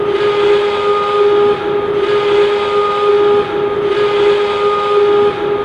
add yet another missing sound effect
alarmSirenLoop.ogg